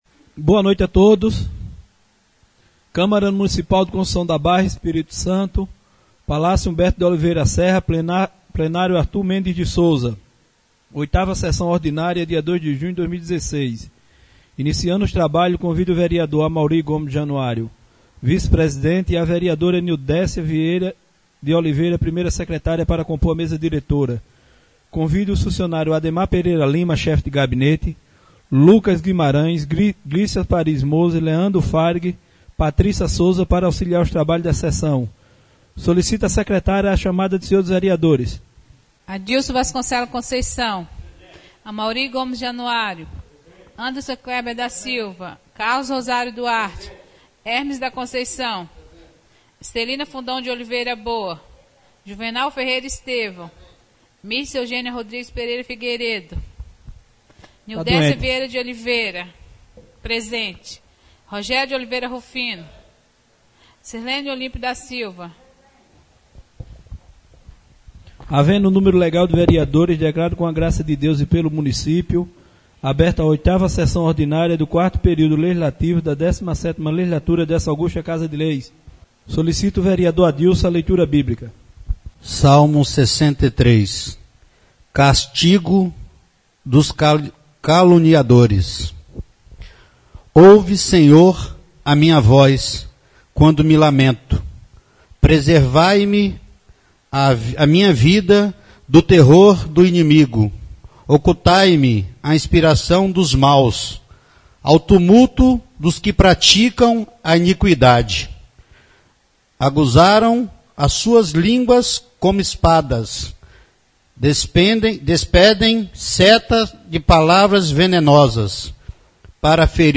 8ªSESSÃO ORDINÁRIA EM 02 DE JUNHO DE 2016 SEDE